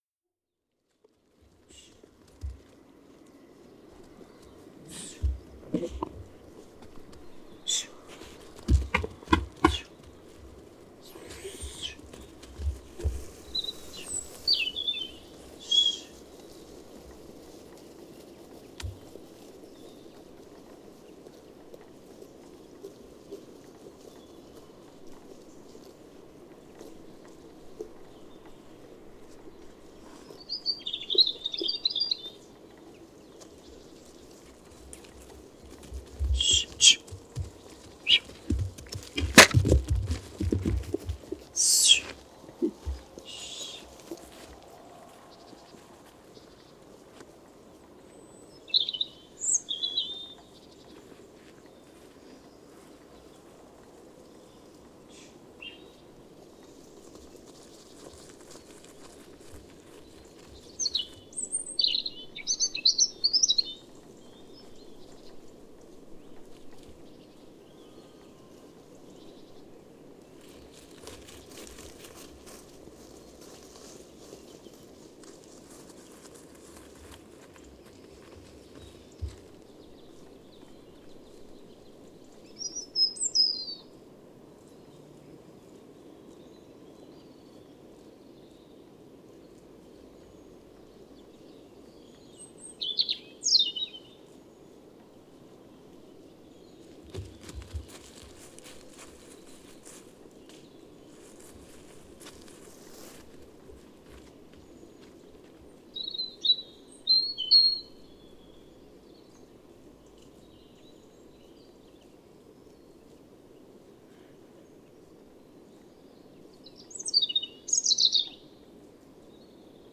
Paisagem sonora de ovelhas a pastar em terreno junto ao aeródromo de Viseu, Lordosa a 25 Fevereiro 2016.
E fica o registo de uma série de vocalizações durante o apascentamento de ovelhas, sem bordão nem cão.
NODAR.00526 – Lordosa: Ovelhas a pastar em terreno junto ao aeródromo de Viseu